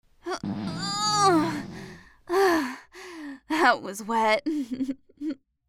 farty5.mp3